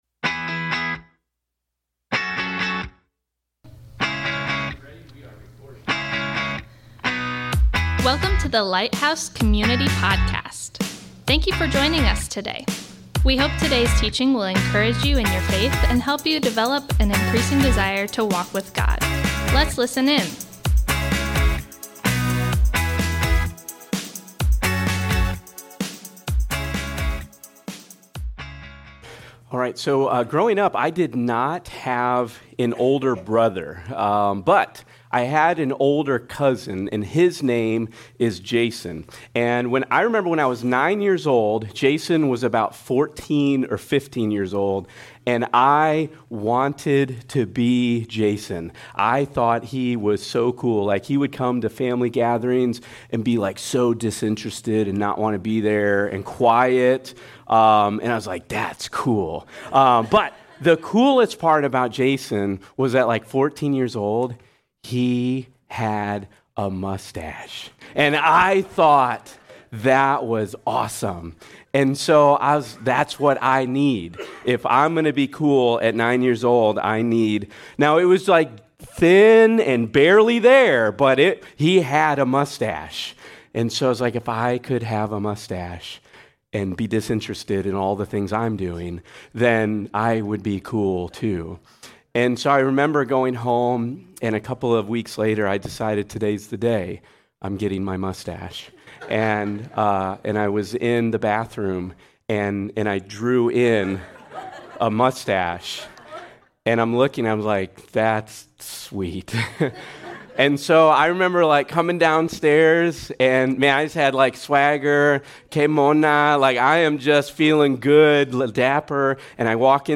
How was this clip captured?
Thanks for joining us today as we worship together.